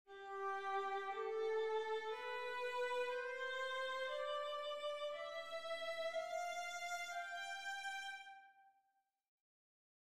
The Mixolydian’s pattern is TTSTTST: almost a major scale (TTSTTTS) but with the last two intervals reversed
Mixolydian
mixolydian.mp3